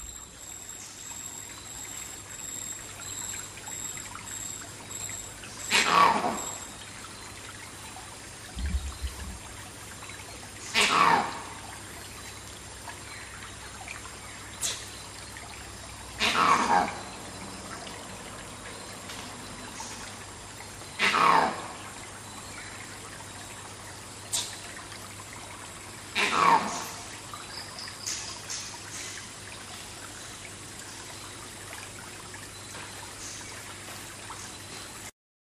Jungle Night Geko or Monkey Call Creepy
borneo call creepy crickets field-recording jungle macaque malaysia sound effect free sound royalty free Animals